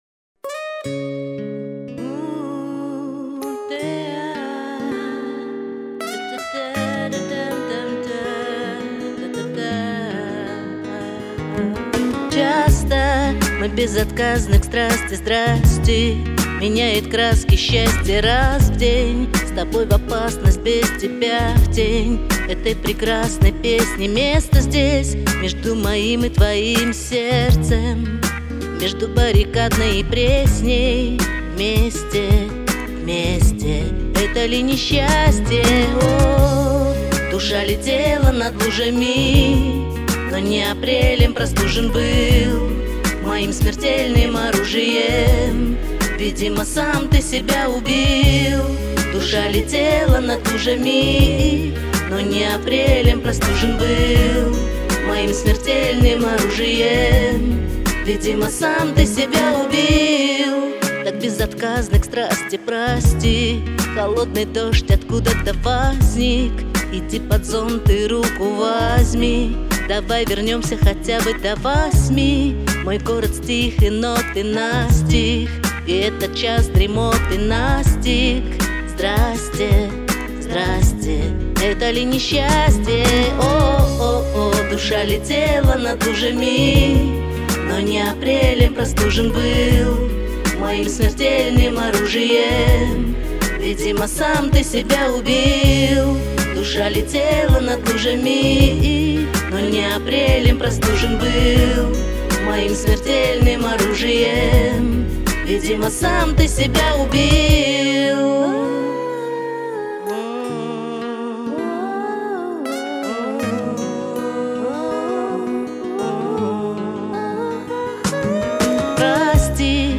Комментарий соперника: Поддерживаю рэпом в женском варианте.